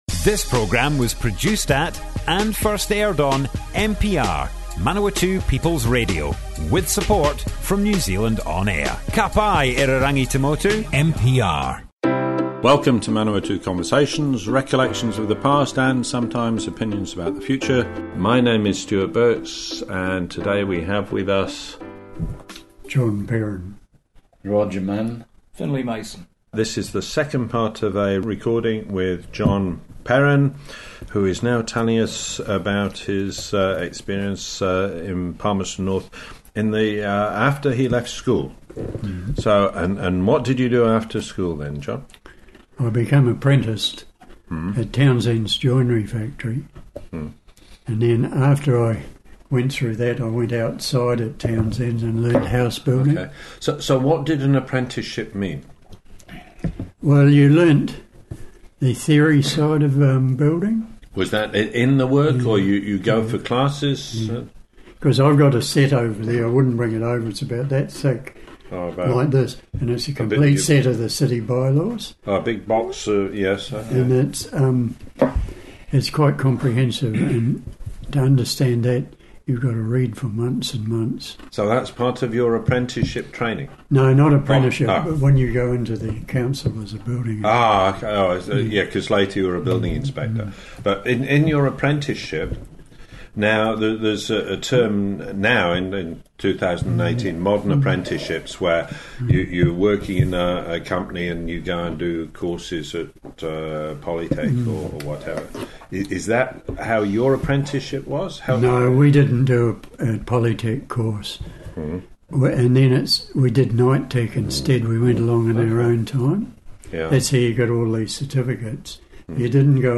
Manawatu Conversations More Info → Description Broqadcast on Manawatu People's Radio, 10 July 2018.